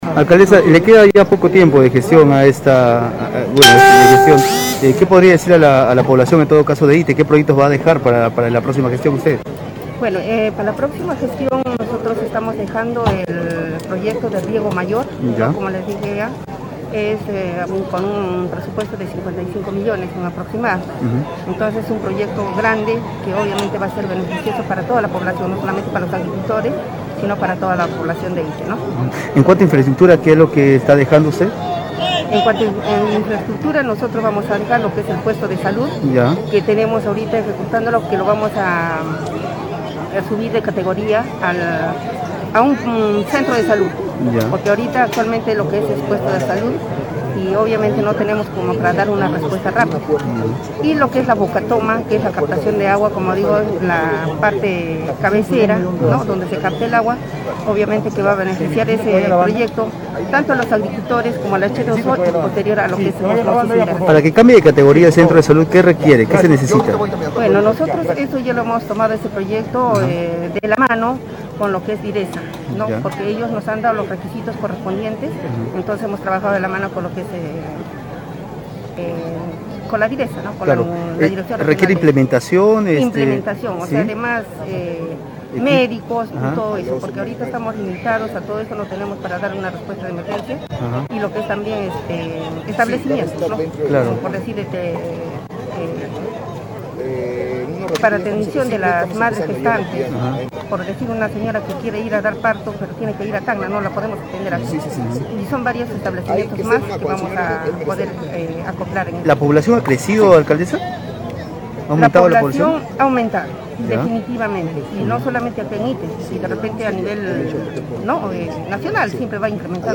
Machaca Mamani brindó declaraciones durante las actividades del 61 aniversario de creación institucional del distrito que dirige.